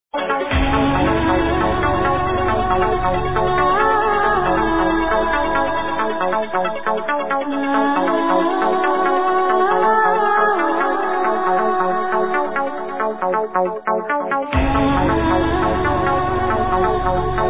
sounds very nice, wouldn't mind knowing this...
It doesn't have any of the bagpipes (like in braveheart).
radio mix I think